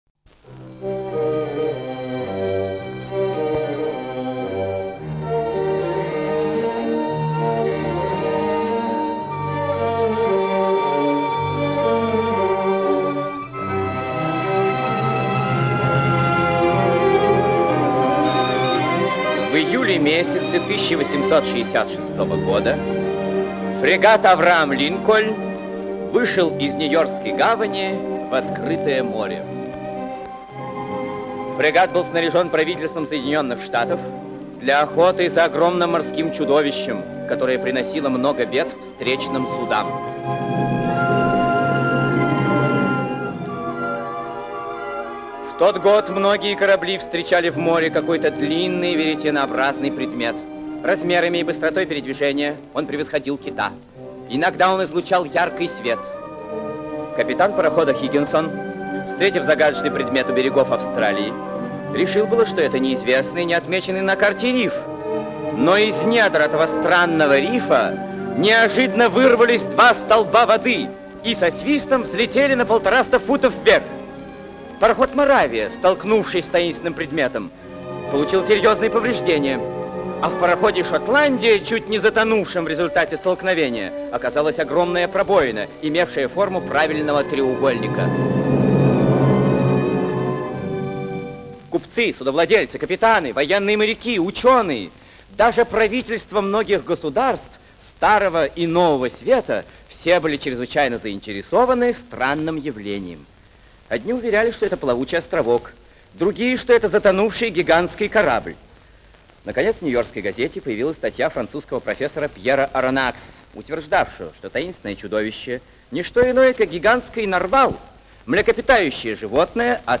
Двадцать тысяч лье под водой - аудио рассказ Жюля Верна.